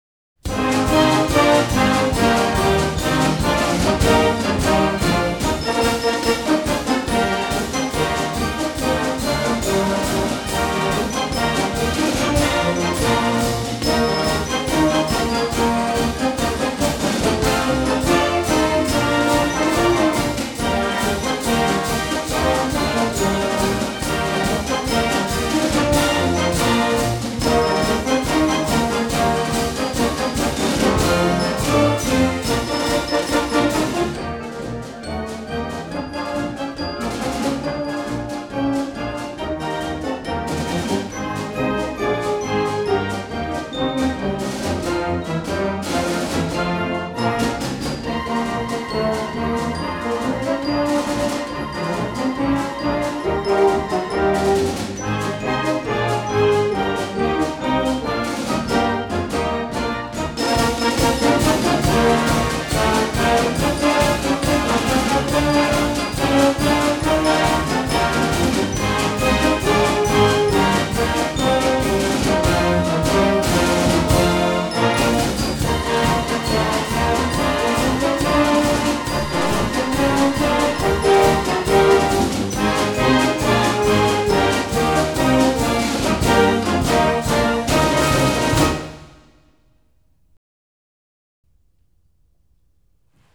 Since most theme music for colleges orginated in the 1920s and 30s, the sound is a traditional one with modernist tweaks: military band arrangements with ragtimey accent, typically played very quickly, often ripped through at lightspeed following big plays in games.
“Notre Dame Victory March,” heavy on the brass and crazy, trilling woodwinds.